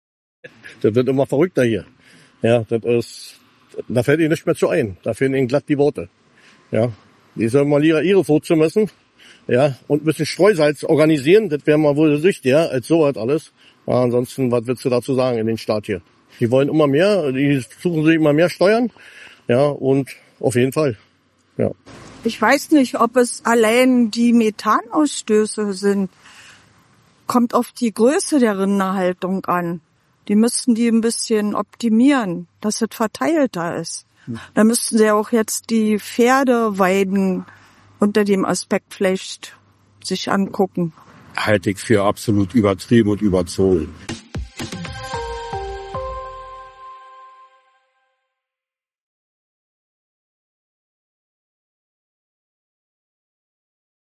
hat sich in Biesenthal nahe Berlin umgehört.